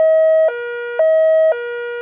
siren.wav